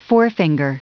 Prononciation du mot forefinger en anglais (fichier audio)
Prononciation du mot : forefinger